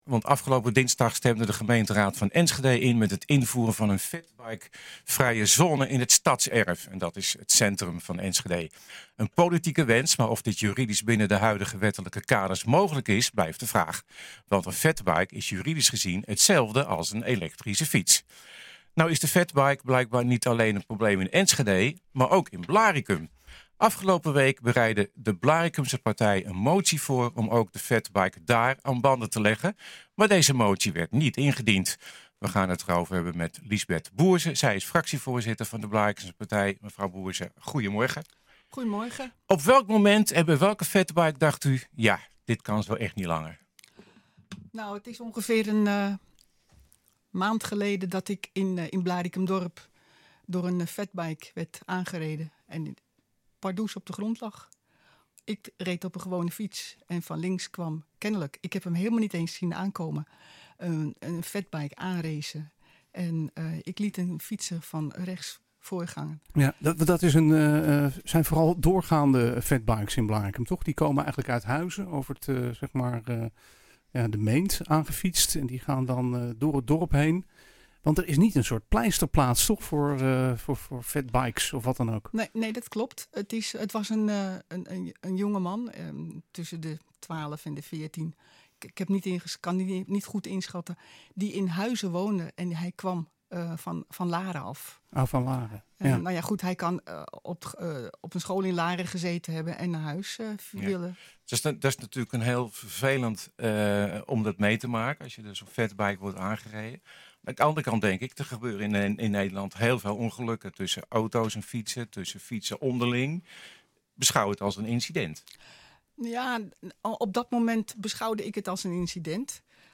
Afgelopen week bereidde ‘De Blaricumse Partij' een motie voor om ook de fatbike daar aan banden te leggen. We gaan het erover hebben met Liesbeth Boersen, fractievoorzitter van de De Blaricumse Partij